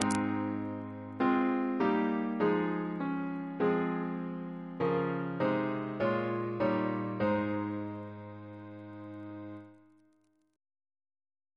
Single chant in A♭ Composer: Jonathan Battishill (1738-1801) Reference psalters: OCB: 18